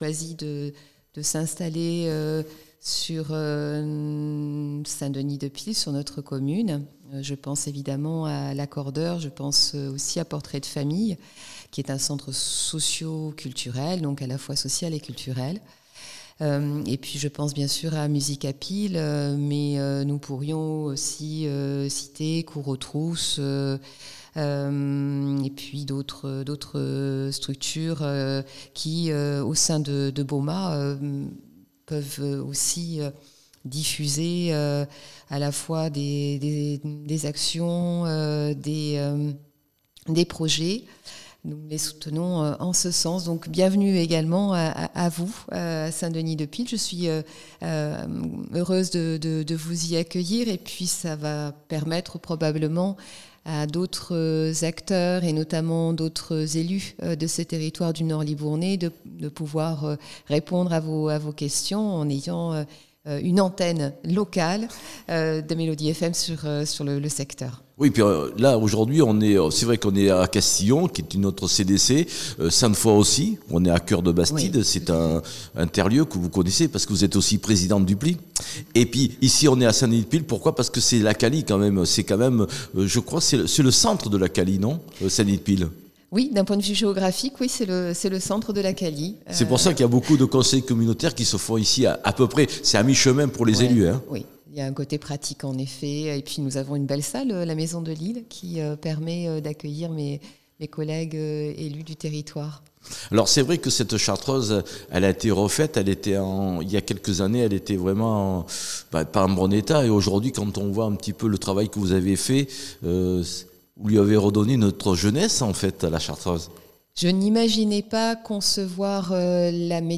Fabienne Fonteneau maire de St Denis de Pile l'interview lors de la conférence de presse de la rentrée ,les projets pour la commune